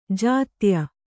♪ jātya